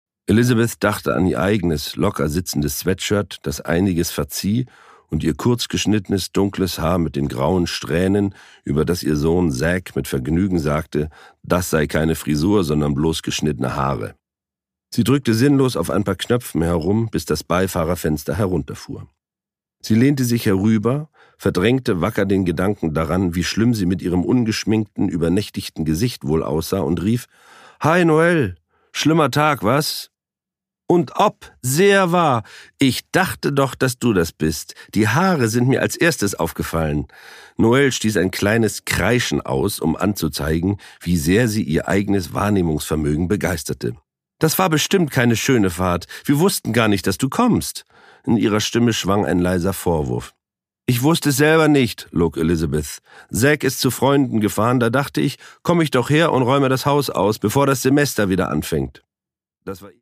Produkttyp: Hörbuch-Download
Gelesen von: Charly Hübner